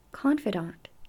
Someone you confide in is your "confidant" or "confidante" (pronounced "KON fuh dont;" hear it here ).
confidant.mp3